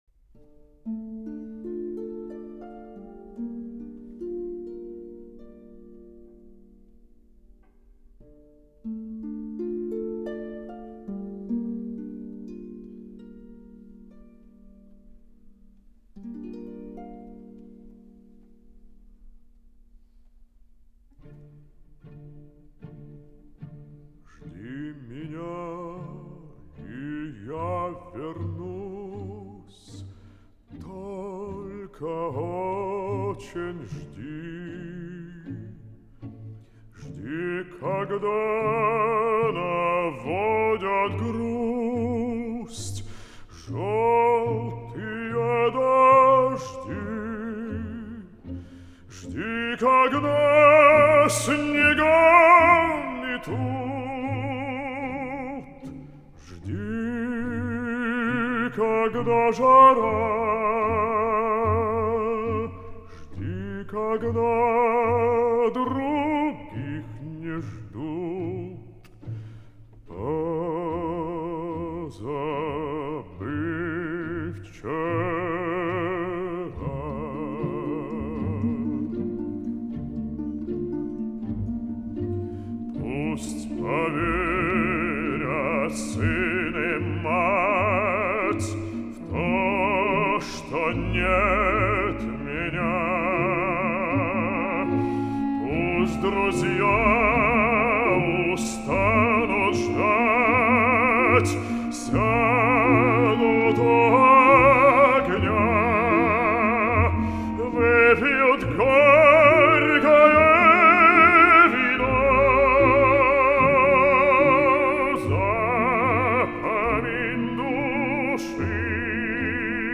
Романс